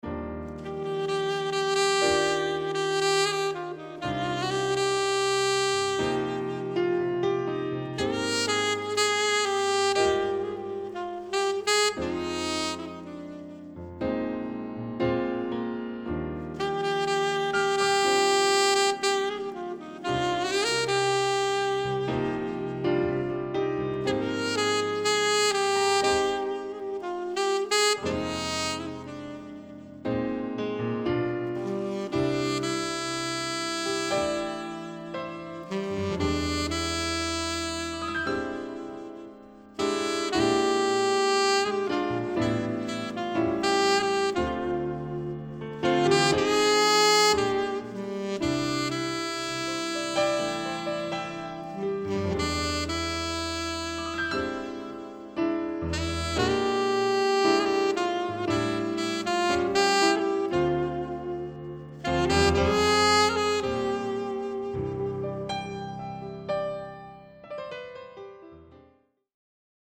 Soprano/Alto/Tenorsax/Altoflute
Piano/Organ/Rhodes
Drums